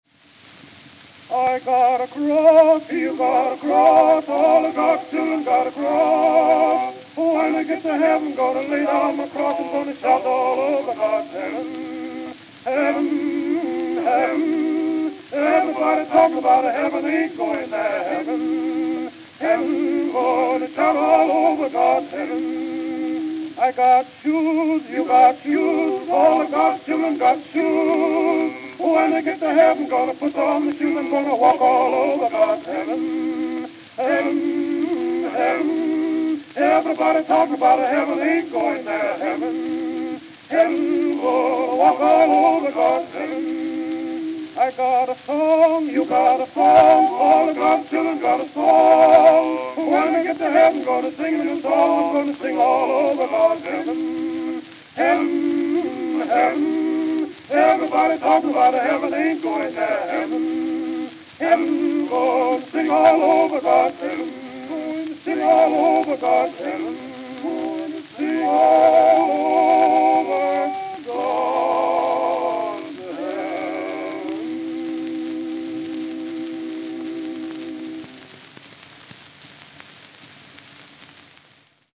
Fisk University Jubilee Quartet Fisk University Jubilee Quartet
Camden, New Jersey Camden, New Jersey